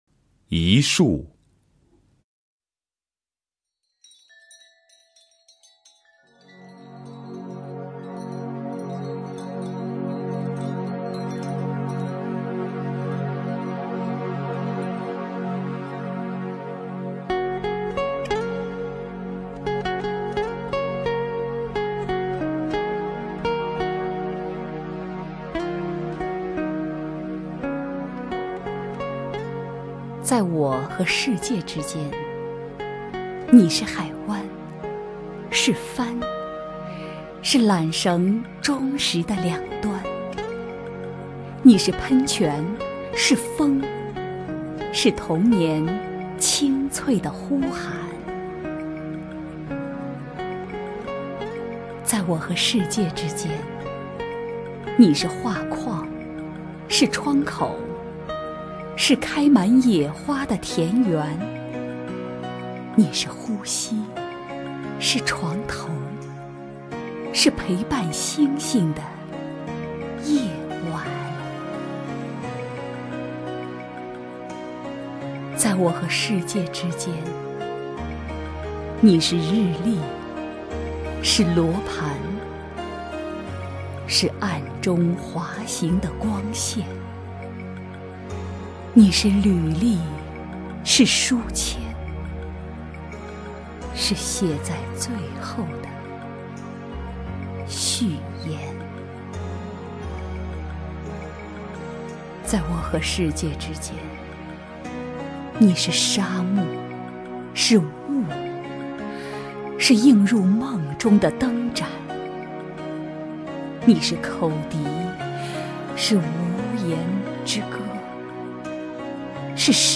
狄菲菲朗诵：《一束》(北岛)
名家朗诵欣赏 狄菲菲 目录